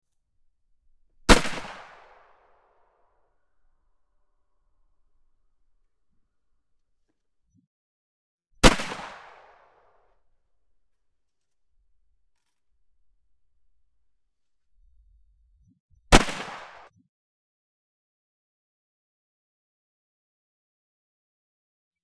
Gunshot Rifle
Gunshot_rifle.mp3